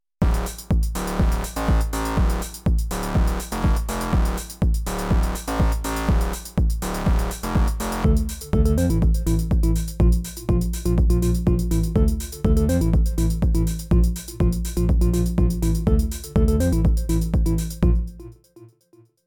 Two tracks with Sy RAW to add the missing sub :stuck_out_tongue:
Edit: with initial riff …